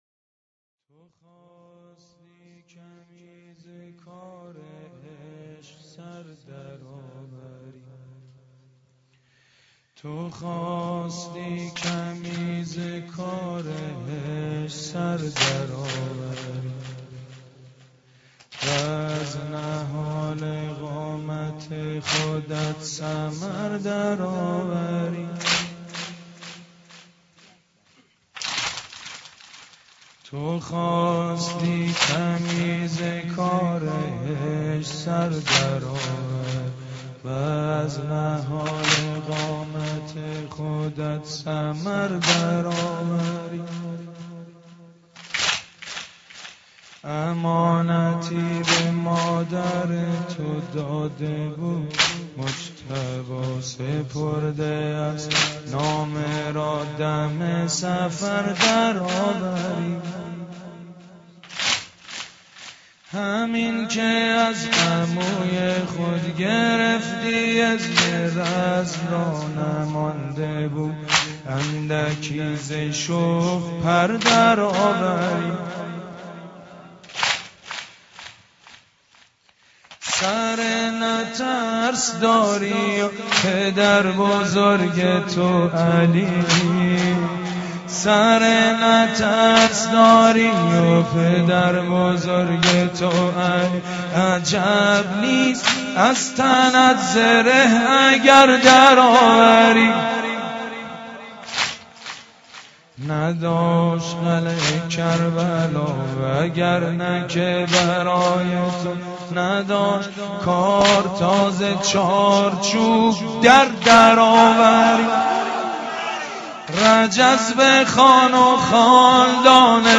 متن سینه زنی واحد شب ششم محرم حضرت قاسم (ع) با سبک سید مجید بنی فاطمه -( تو خواستی کمی ز کار عشق سر در آوری )